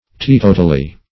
teetotally - definition of teetotally - synonyms, pronunciation, spelling from Free Dictionary Search Result for " teetotally" : The Collaborative International Dictionary of English v.0.48: Teetotally \Tee*to"tal*ly\, adv. Entirely; totally.